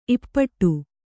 ♪ ippaṭṭu